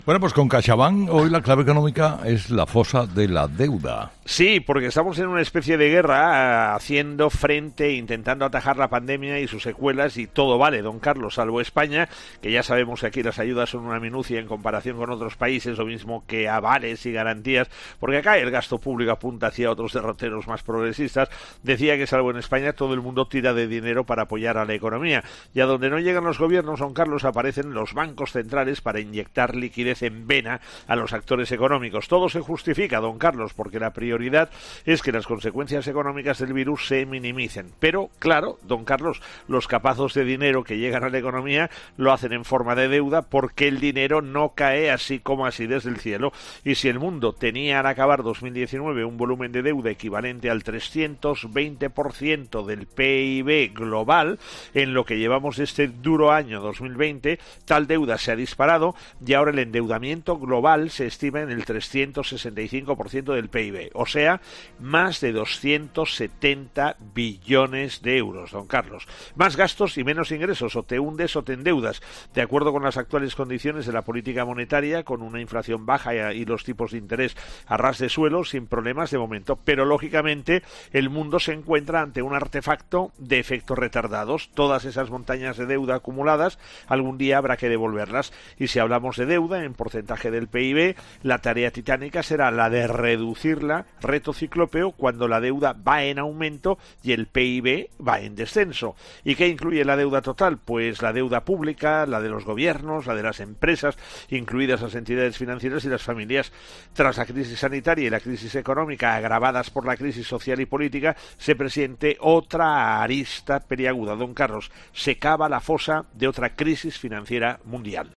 El profesor José María Gay de Liébana analiza en'Herrera en COPE’ las claves económicas del día.